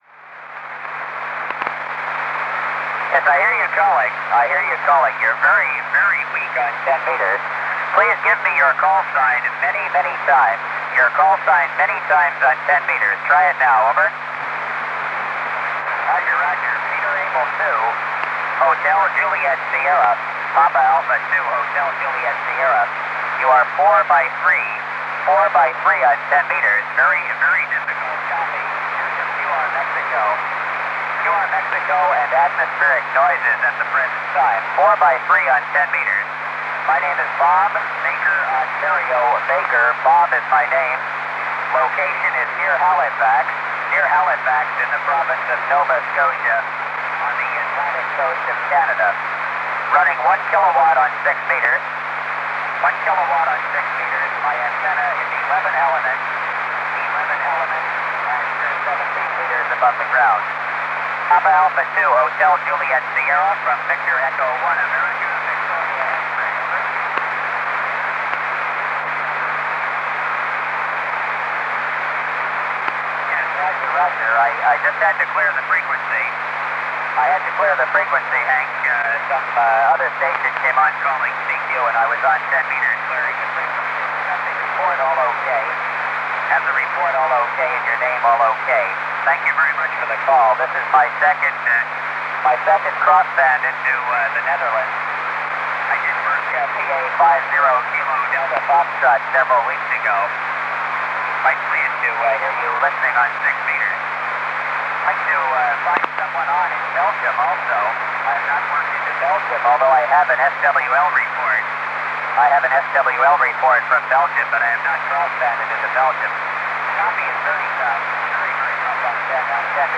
I have always had a tape recorder at hand in my shack.